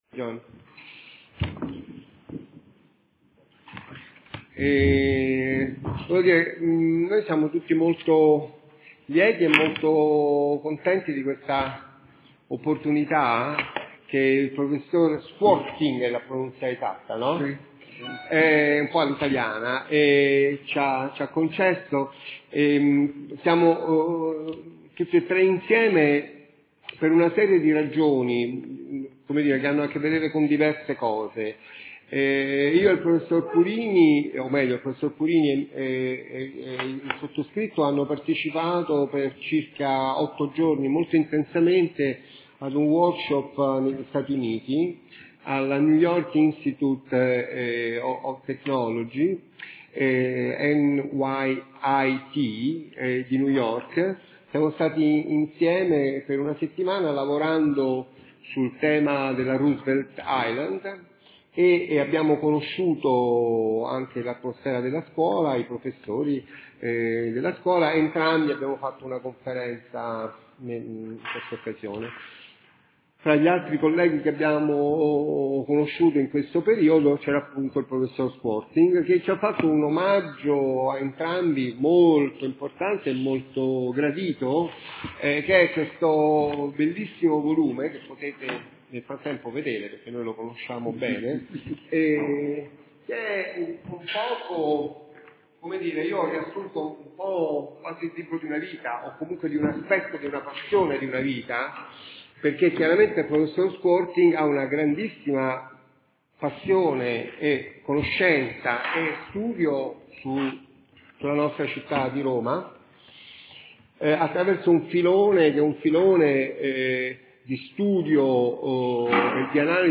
Conferenza